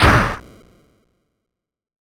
signExplode.ogg